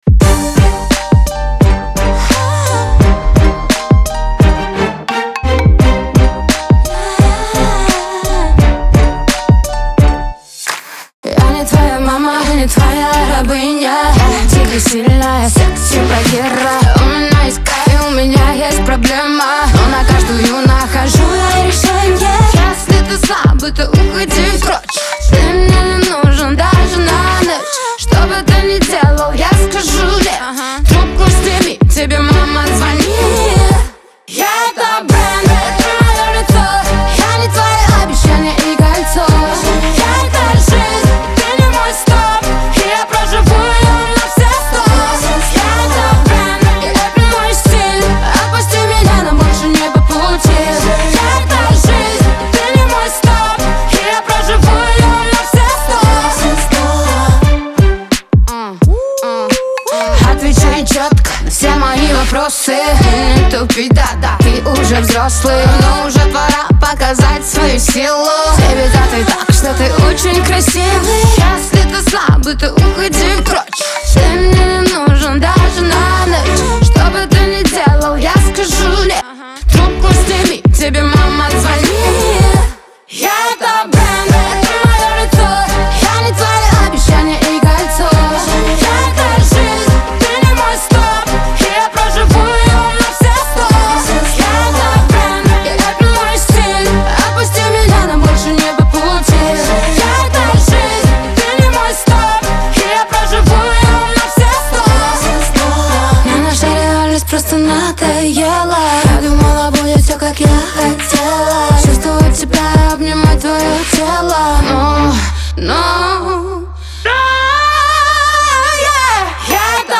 Лирика
эстрада , pop